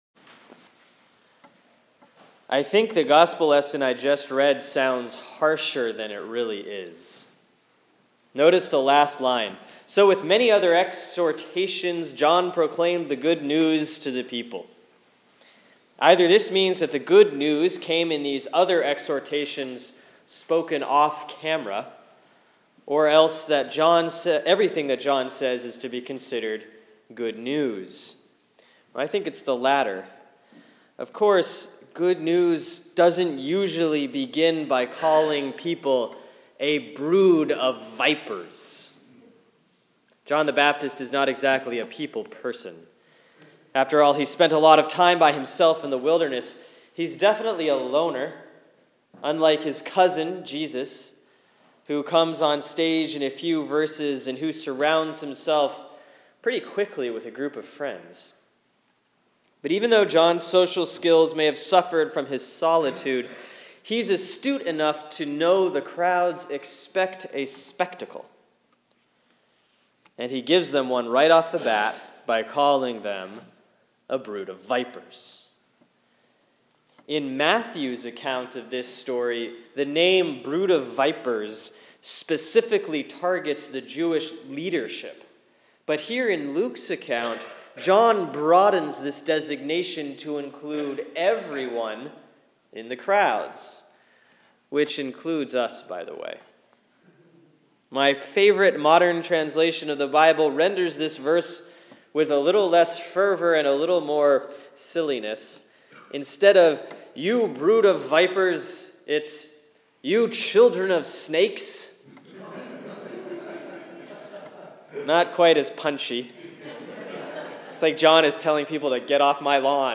Sermon for Sunday, December 13, 2015 || Advent 3C || Luke 3:7-18